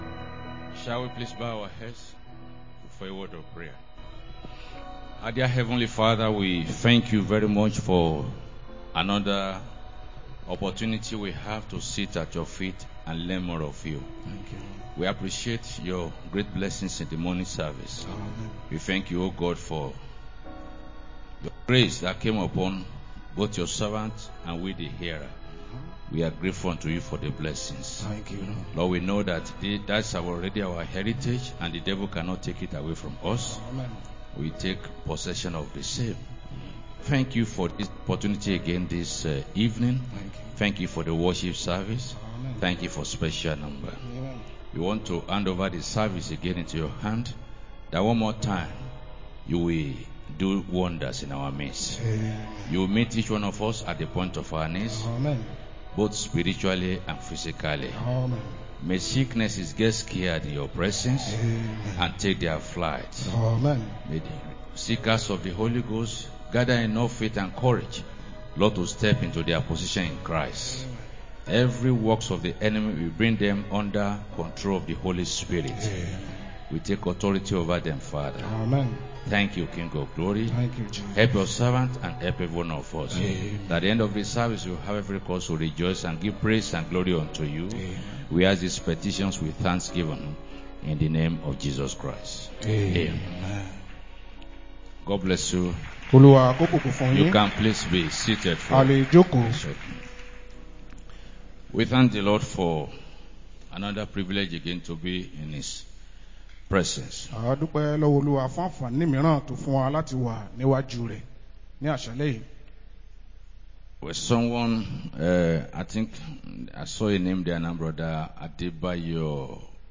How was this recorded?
2025 Believers' Convention | 05-09-25 | Evening Service